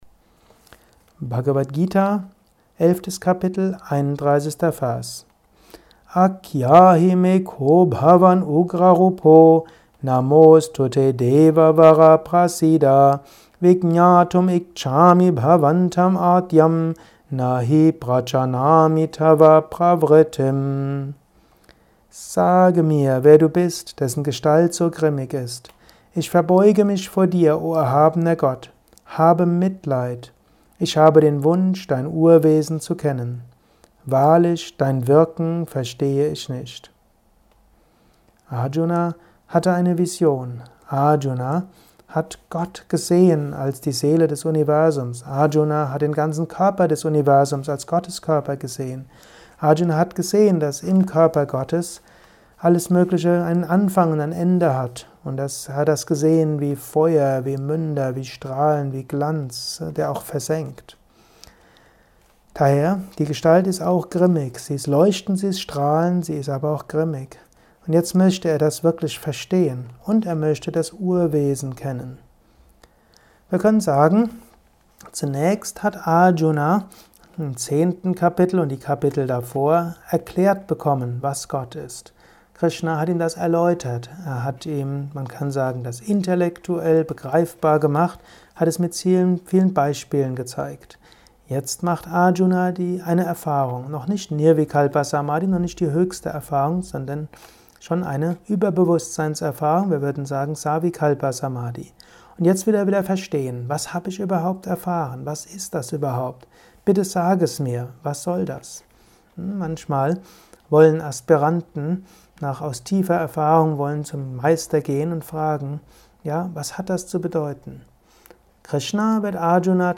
ein kurzer Kommentar als Inspiration für den heutigen Tag